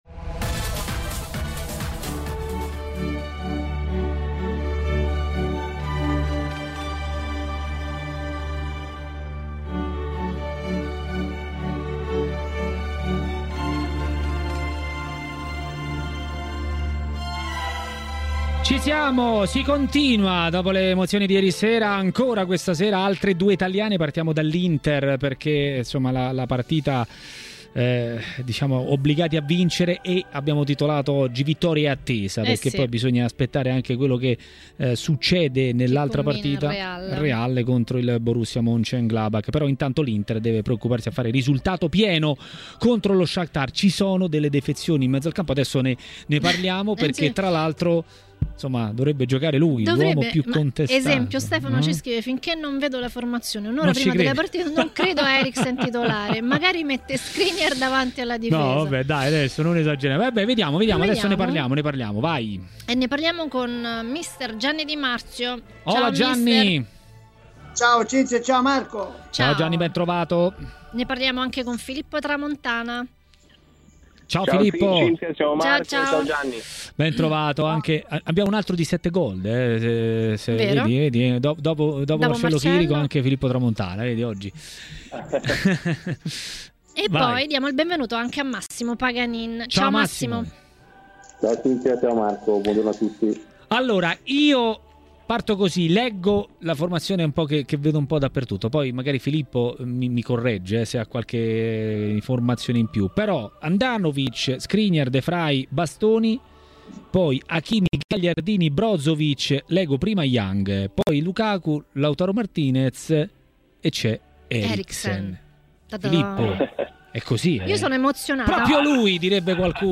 L'ex calciatore e opinionista tv Massimo Paganin a TMW Radio, durante Maracanà, ha parlato dell'Inter in Champions.